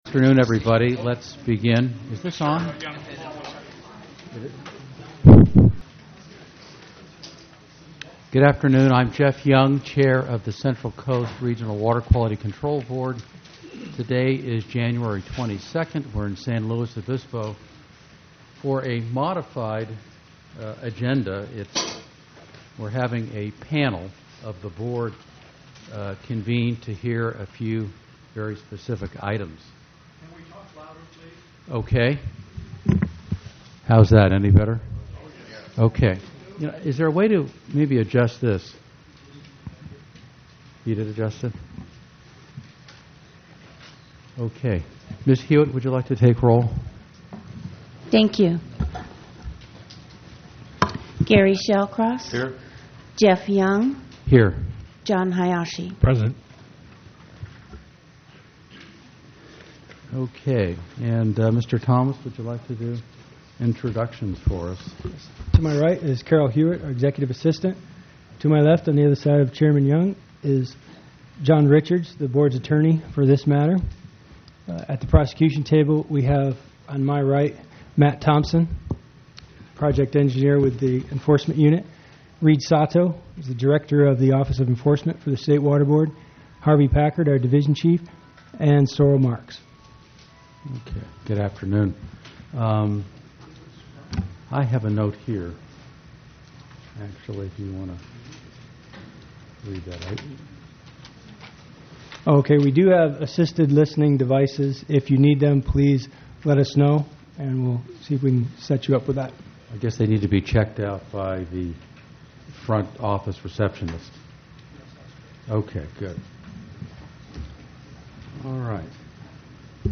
item1_2_roll_call_and_intros.mp3